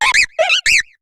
Cri de Capidextre dans Pokémon HOME.